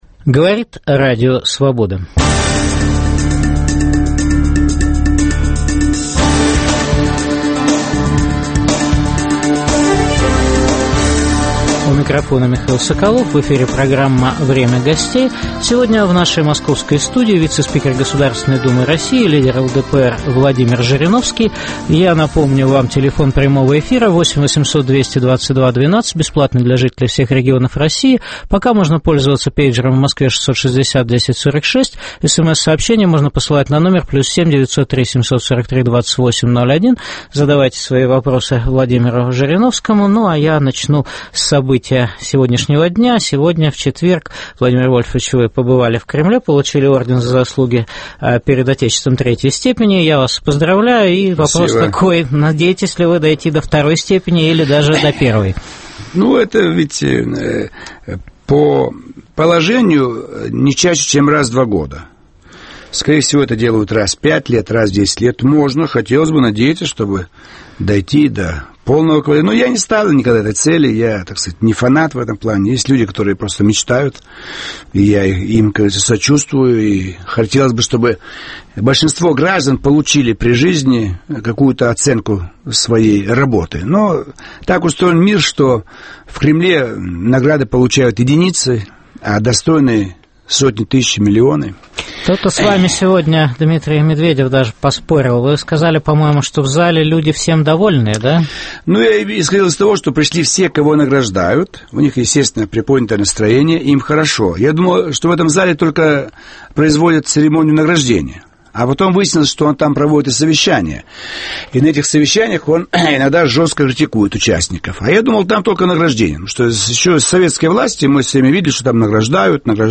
В программе выступит вице-спикер Государственной Думы России лидер ЛДПР Владимир Жириновский.